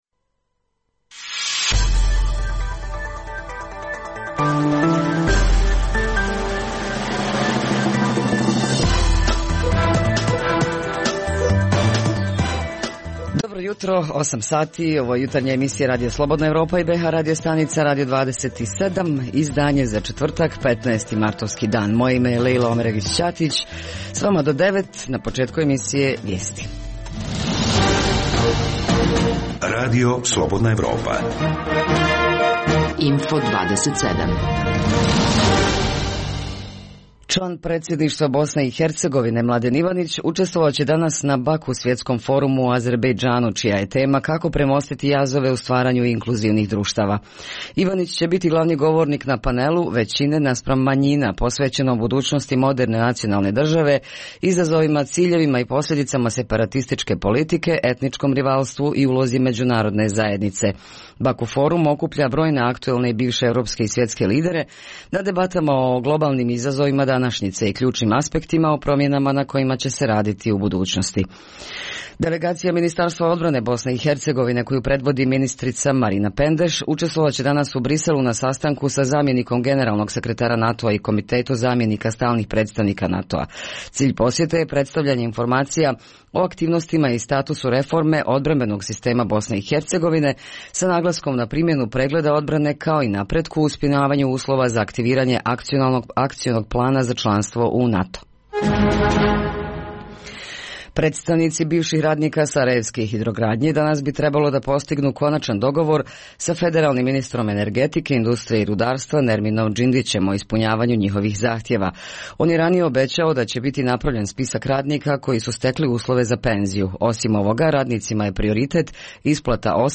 Poslušajte šta su zabilježili naših dopisnici iz Doboja, Mostara i Banja Luke.